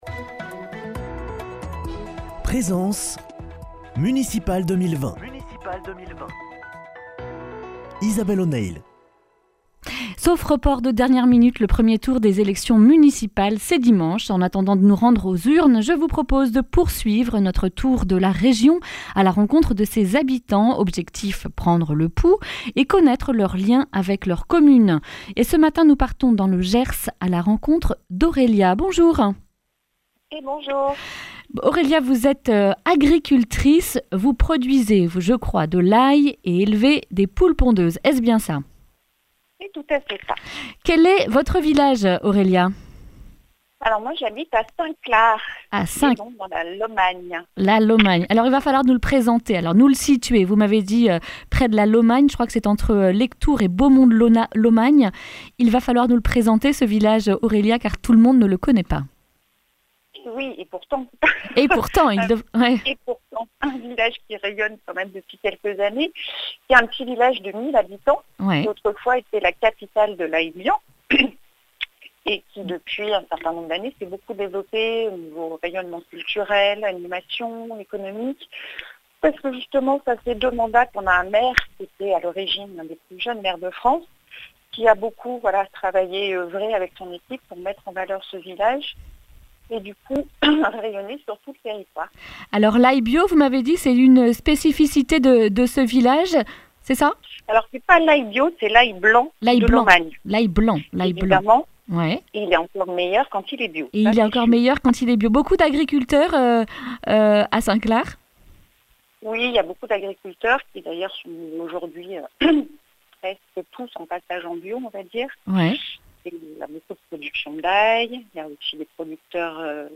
jeudi 12 mars 2020 Le grand entretien Durée 11 min